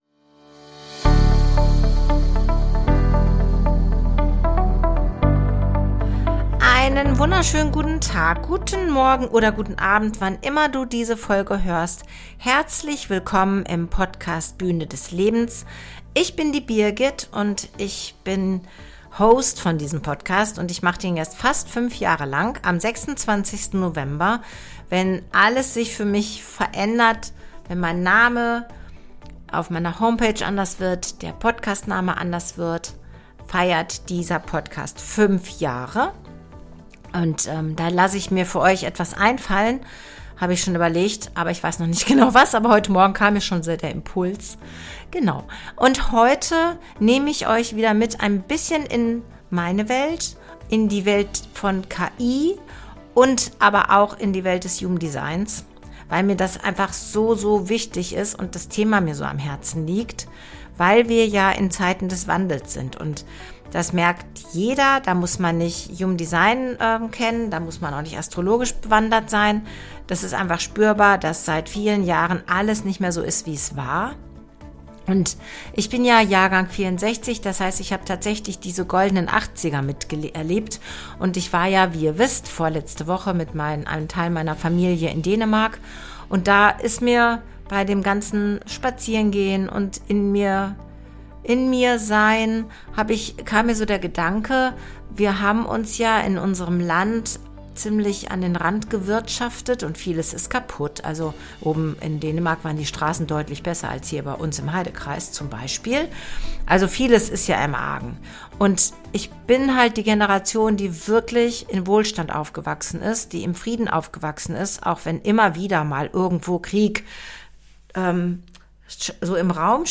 Zum Abschluss hörst du den neuen Song „Ich bau auf mich“ – ein Lied über Echtheit, Selbstvertrauen und Aufbruch.